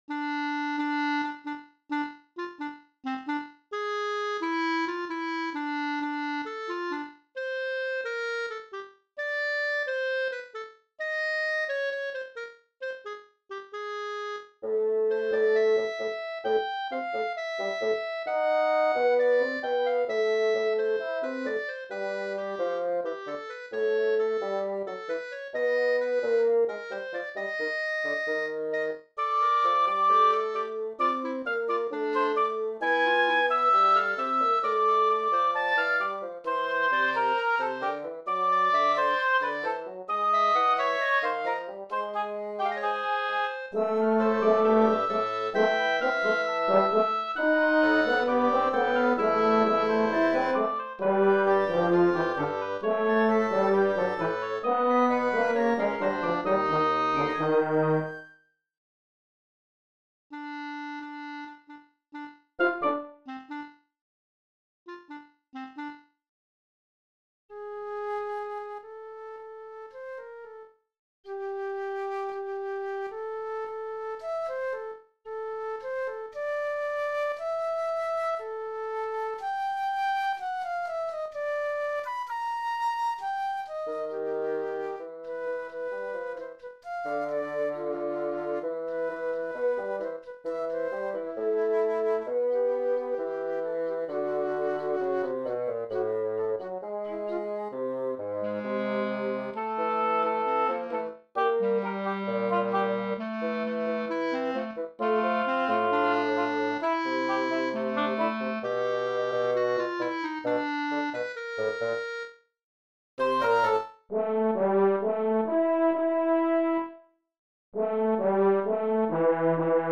Wind Quintet) Winds/Chamber 2016